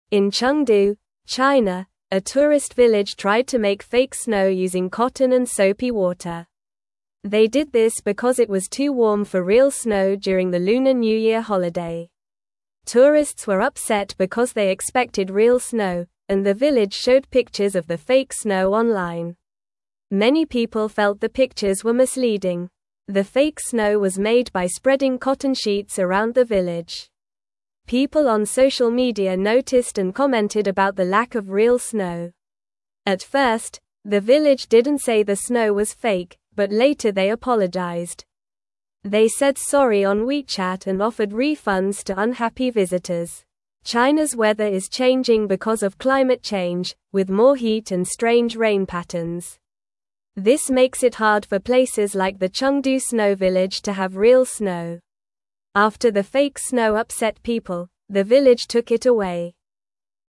Normal
English-Newsroom-Lower-Intermediate-NORMAL-Reading-Village-Makes-Fake-Snow-for-Lunar-New-Year-Fun.mp3